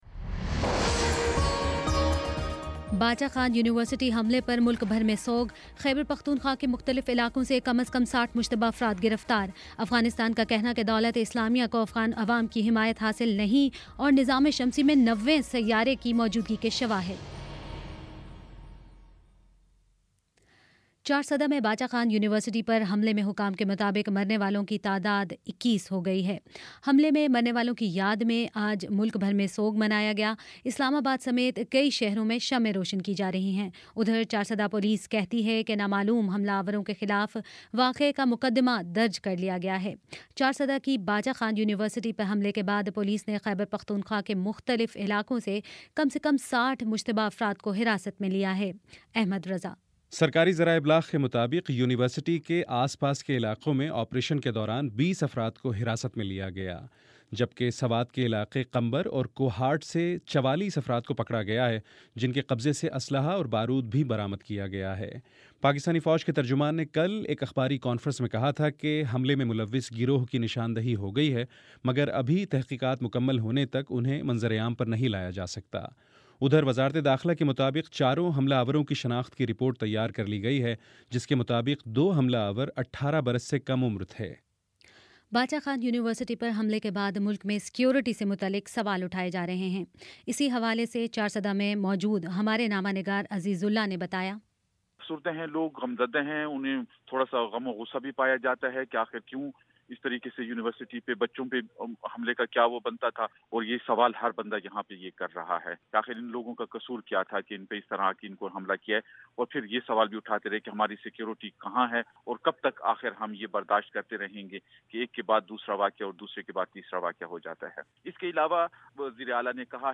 جنوری 21 : شام سات بجے کا نیوز بُلیٹن